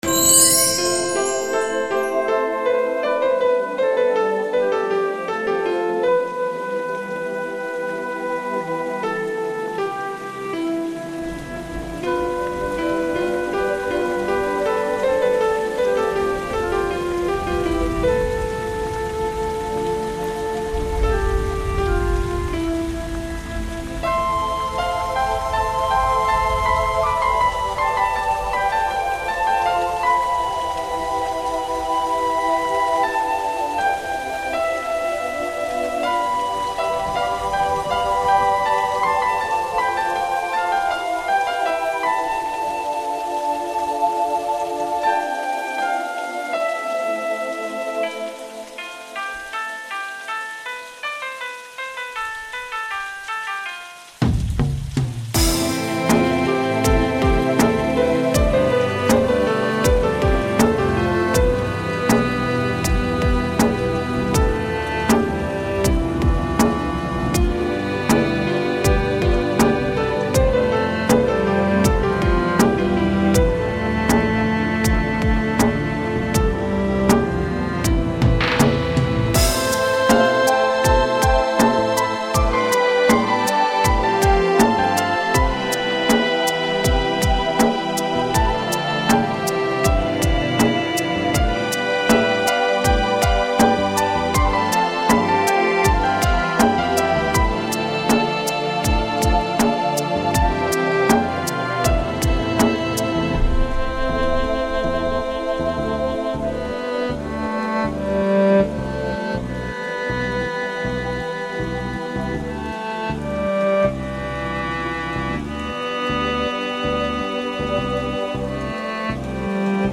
نسخه صوتی ساخته شده یک قطعه موسیقی با بالاترین کیفیت